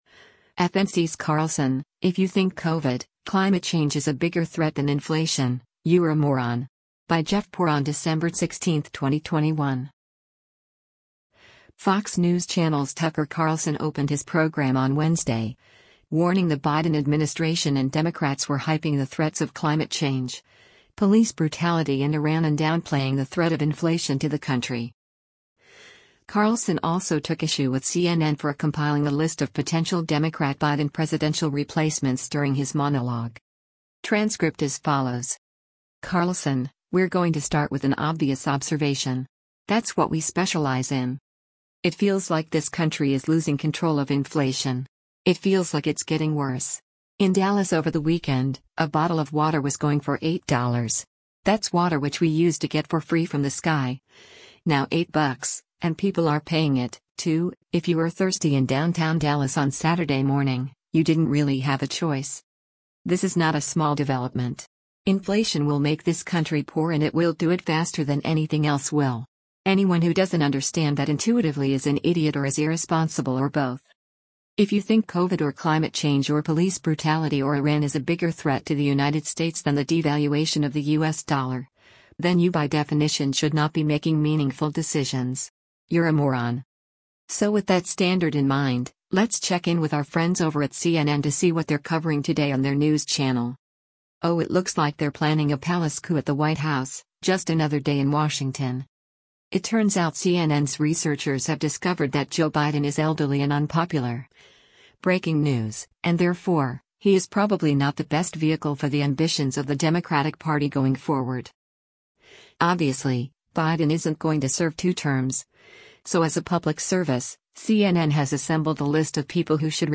Fox News Channel’s Tucker Carlson opened his program on Wednesday, warning the Biden administration and Democrats were hyping the threats of climate change, police brutality and Iran and downplaying the threat of inflation to the country.
Carlson also took issue with CNN for compiling a list of potential Democrat Biden presidential replacements during his monologue.